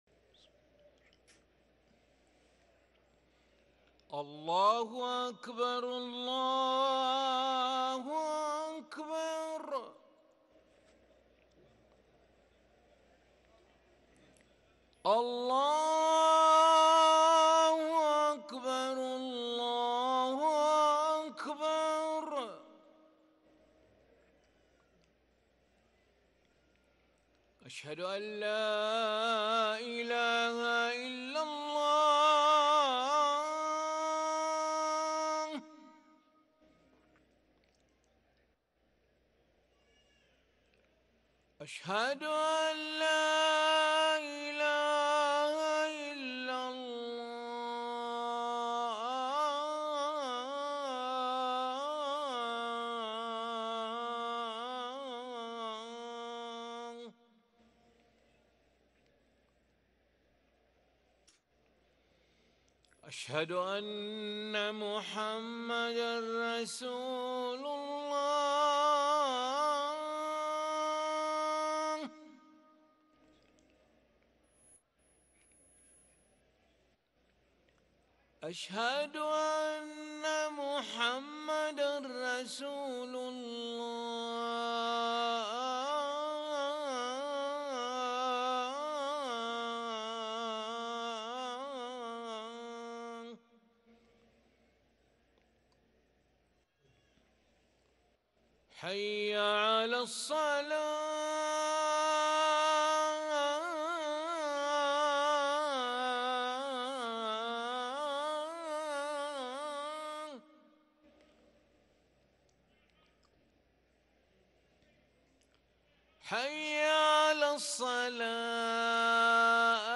أذان العشاء للمؤذن علي ملا الخميس 9 ربيع الآخر 1444هـ > ١٤٤٤ 🕋 > ركن الأذان 🕋 > المزيد - تلاوات الحرمين